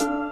50 Synth2.wav